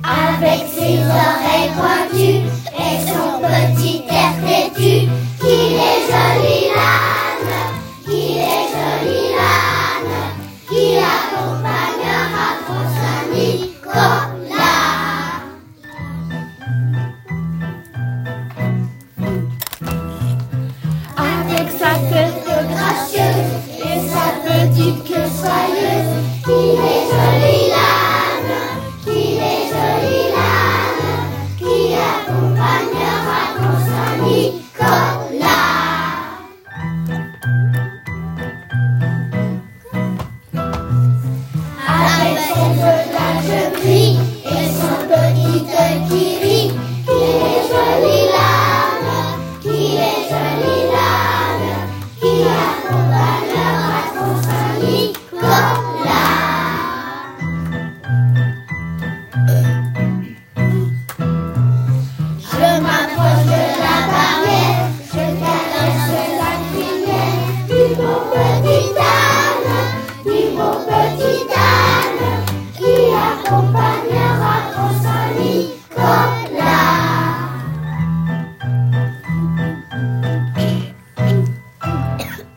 A écouter sur le sentier de Noël !
Chants de Noël interprétés par les élèves de 1-2H et 4H